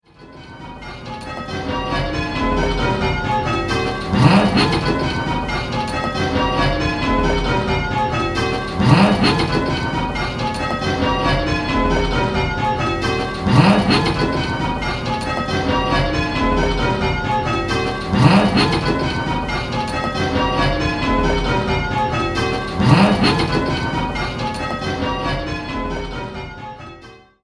In this game you will be answering similar questions, but with 10 bells to choose from, the choices are much larger and there are more different notes to identify!